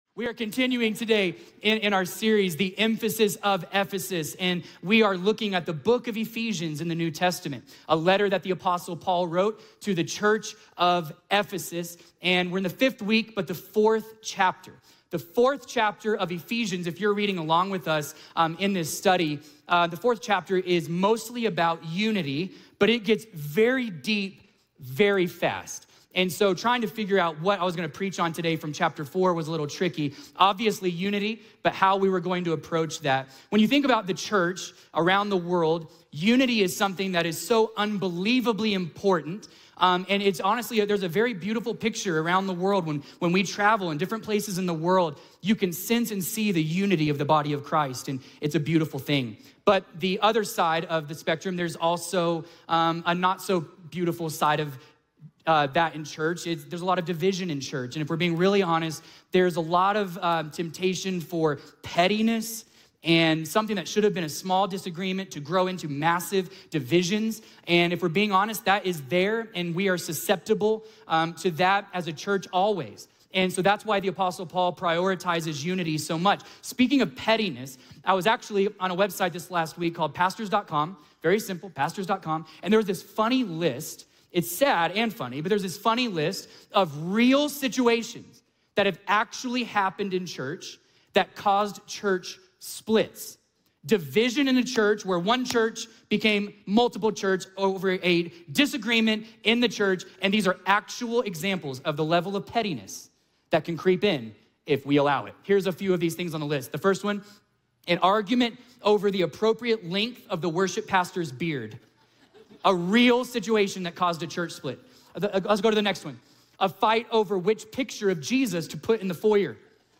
A message from the series "The Emphasis of Ephesus." In this week's message, we uncover the historical significance of Ephesus and how Paul's teachings still speak to us today.